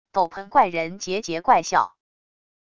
斗篷怪人桀桀怪笑wav音频